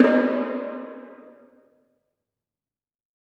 PERC - TWEET.wav